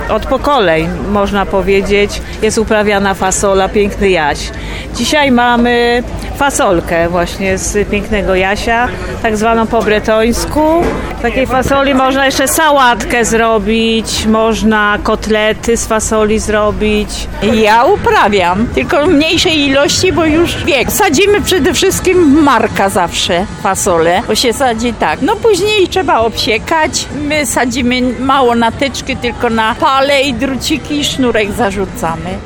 Dzisiaj mamy fasolkę po bretońsku, ale można też robić sałatki z fasoli, albo kotlety – mówiła jedna z mieszkanek Zakliczyna.